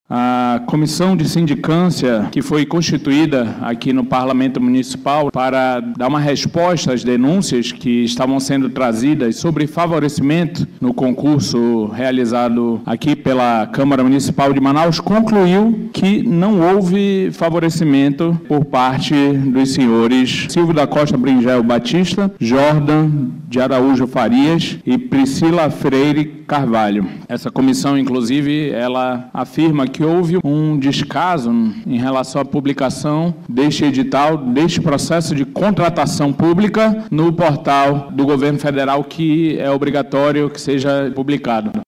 Durante discurso, na tribuna do Parlamento Municipal, o vereador Rodrigo Guedes, do Republicanos, disse que o relatório da Comissão de Sindicância da Câmara Municipal de Manaus – CMM não confirmou a suspeita de favorecimento durante o certame.